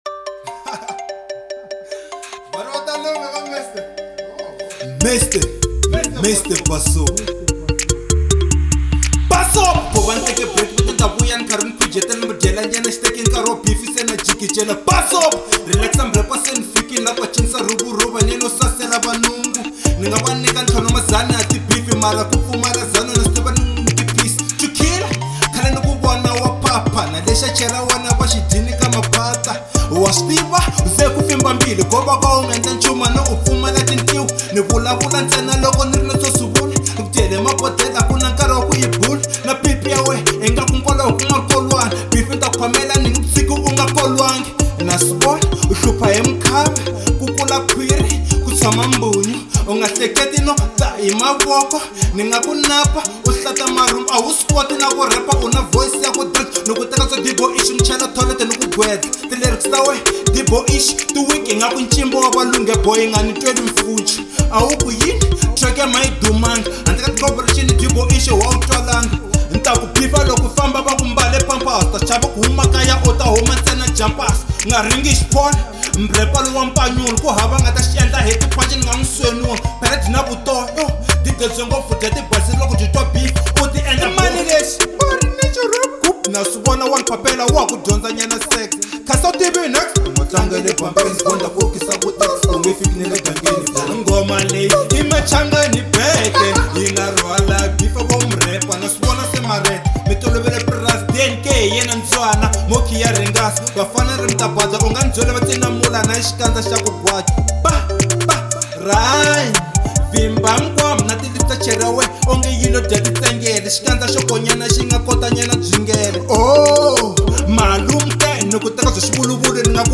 03:33 Genre : Hip Hop Size